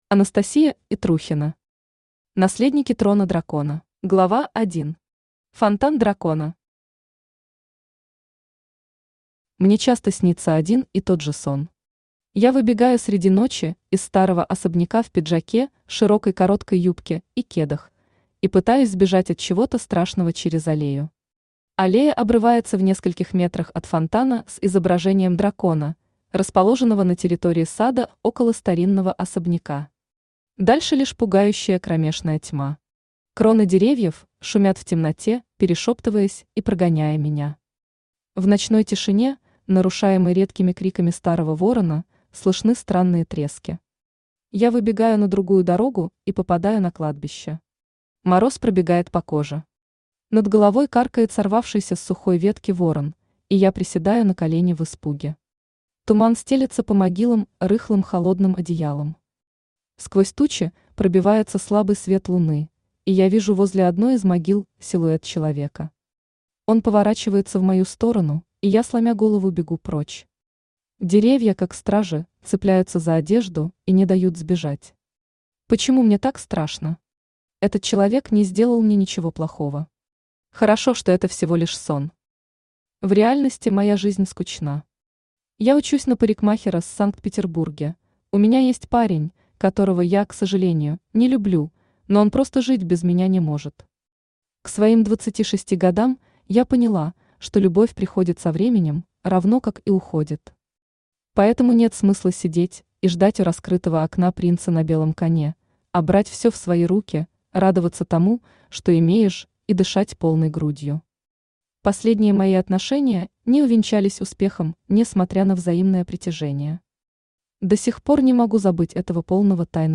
Аудиокнига Наследники трона дракона | Библиотека аудиокниг
Aудиокнига Наследники трона дракона Автор Анастасия Итрухина Читает аудиокнигу Авточтец ЛитРес.